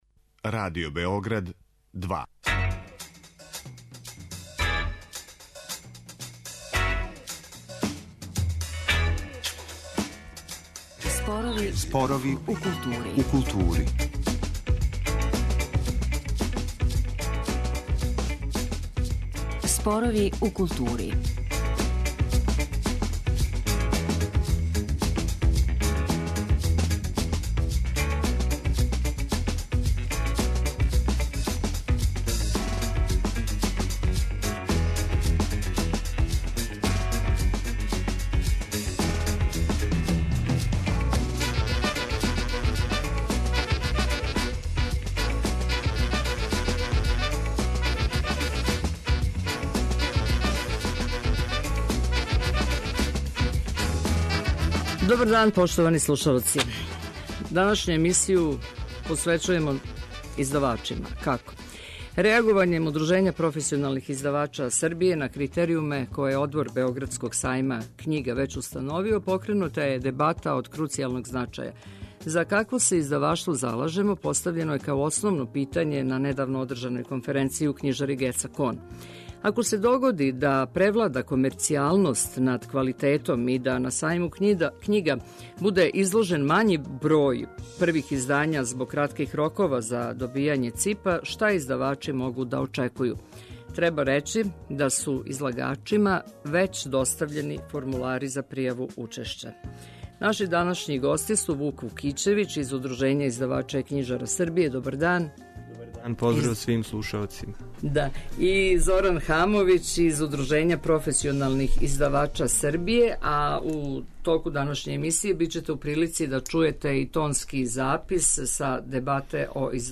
Слушаоци ће бити у прилици да чују тонске записе са дебате о издаваштву.